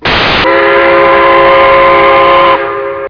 AMERICAN WHISTLES
cnj3chime.wav